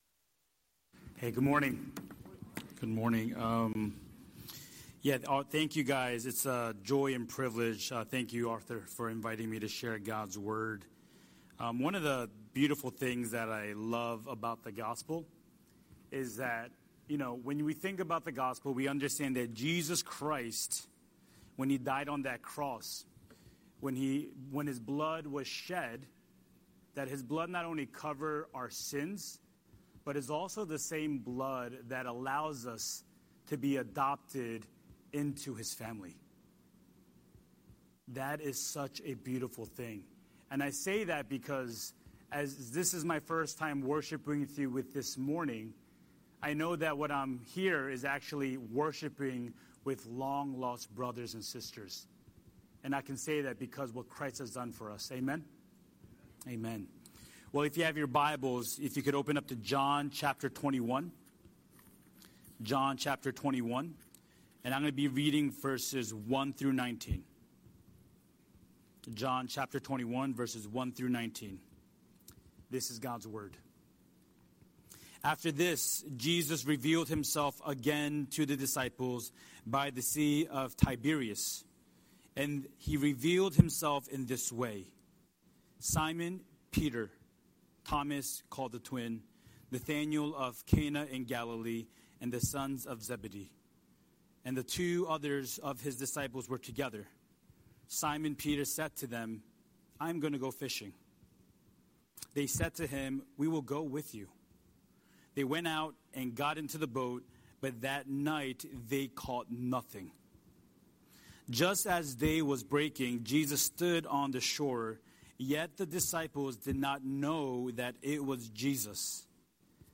Scripture: John 21:1–19 Series: Sunday Sermon